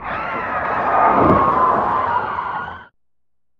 skidin2.ogg